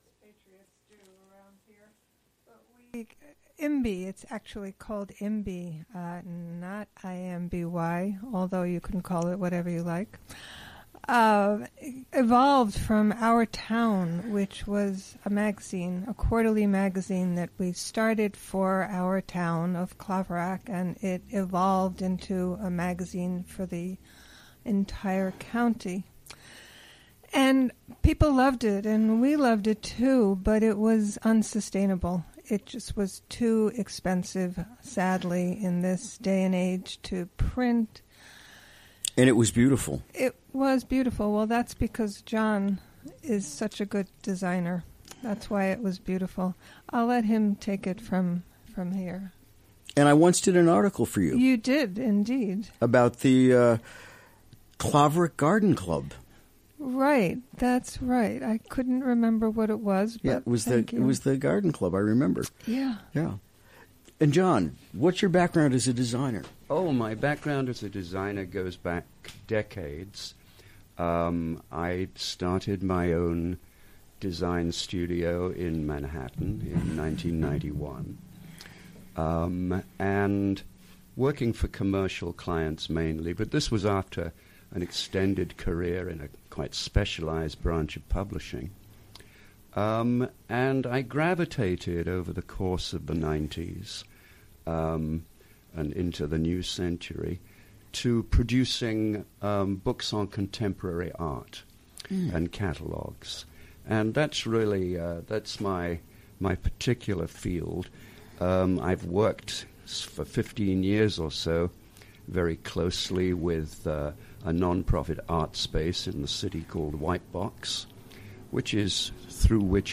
Recorded during the WGXC Morning Show on Wednesday, May 3.